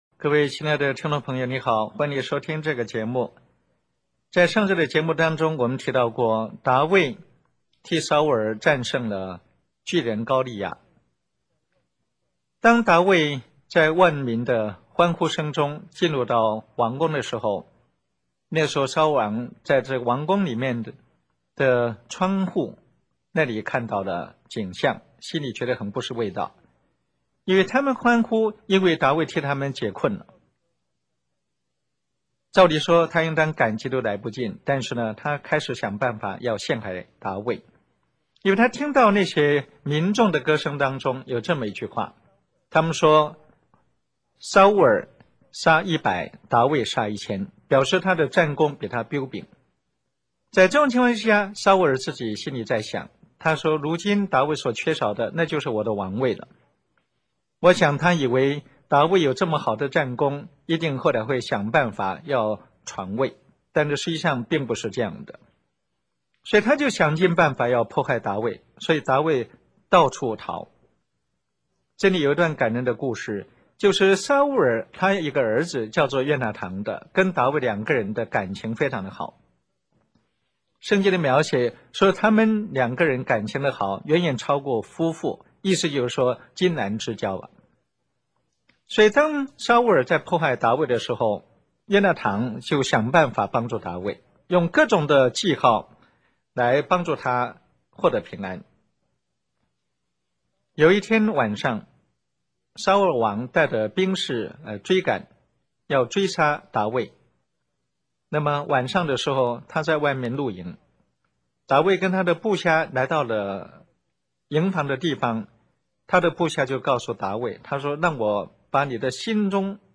以晓畅生动的语调，层次分明地主持这个精采的信仰讲座
我们重新把电台播放过的节目放在这里，供大家收听。